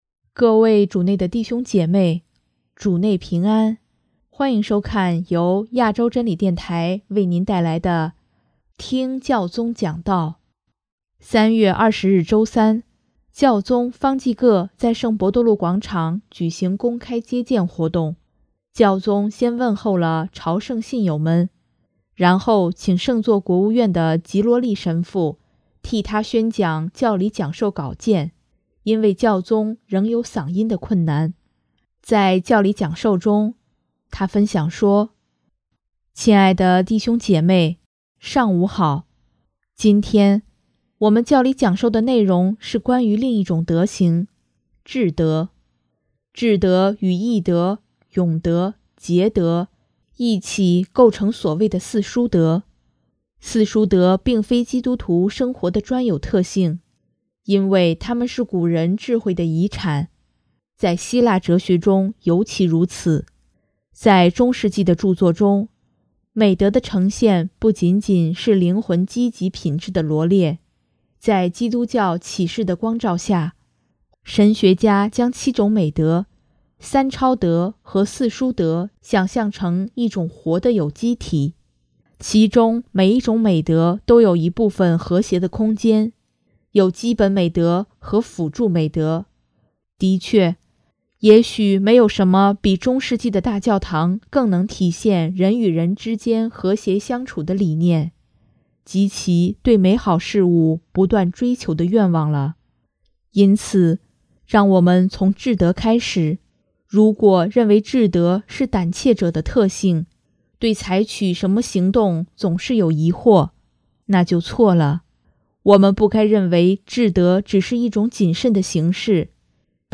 【听教宗讲道】|天主要我们不仅成圣，也要成为“明智的圣人”